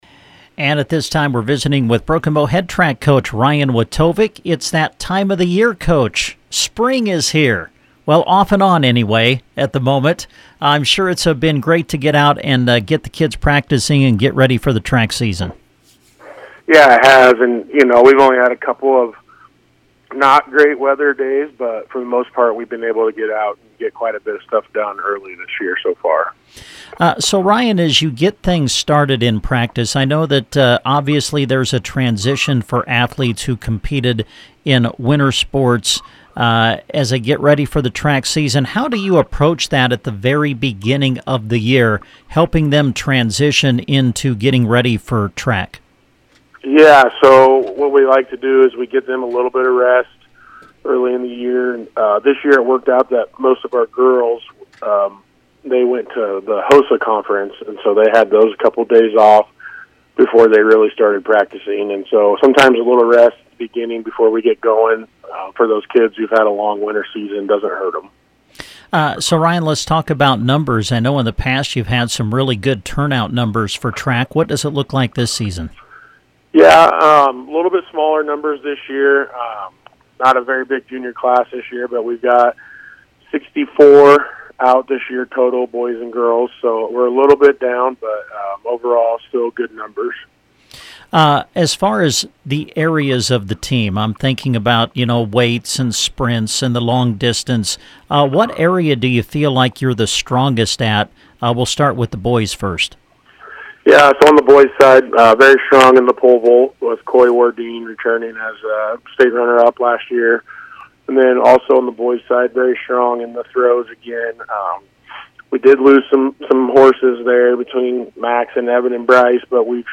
BOW-TRACK-INTERVIEW_.mp3